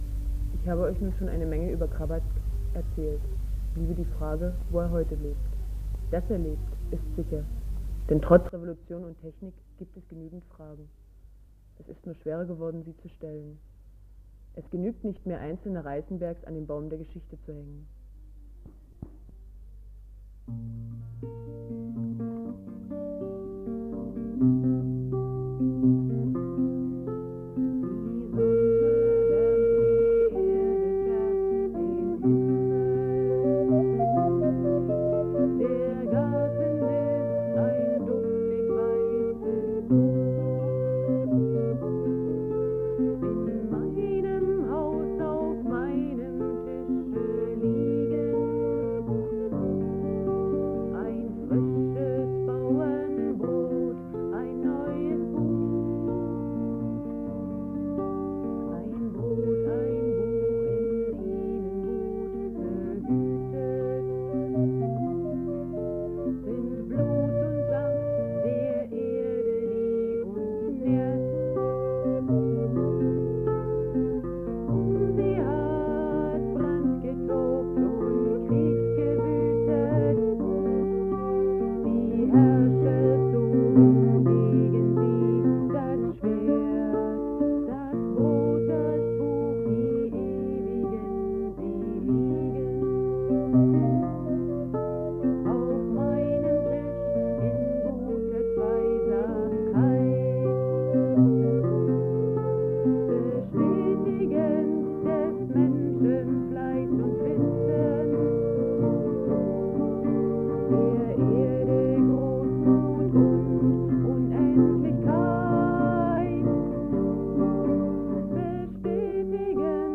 Gesang, Sprecher
Text Sprecher, Klavier
Violine